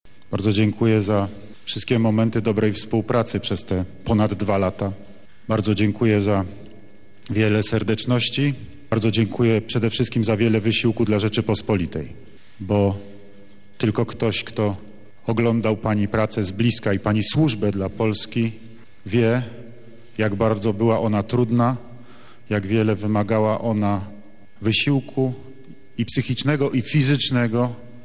Podczas ceremonii w Pałacu Prezydenckim Andrzej Duda podziękował Beacie Szydło za wspólną pracę.